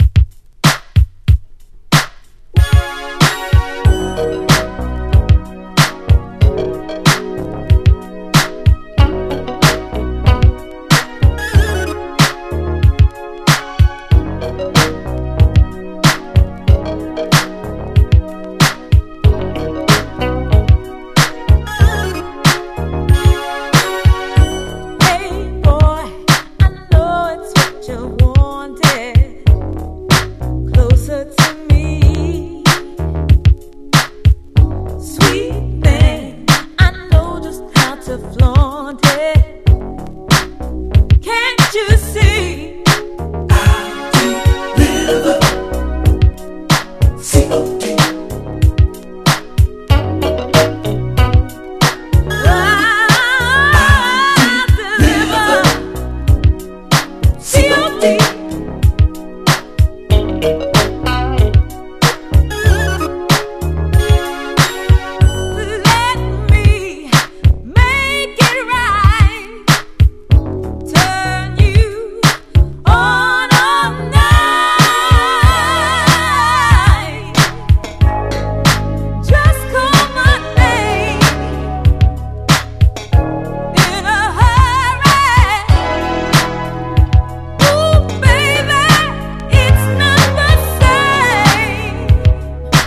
ネタとしても楽曲としても名曲揃いのメロウ・アルバム！
歯切れのよいタイトなリズム・ワークがカッコ良過ぎるジャズ・ファンク